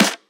Boom-Bap Snare 91.wav